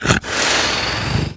zombie_pain6.mp3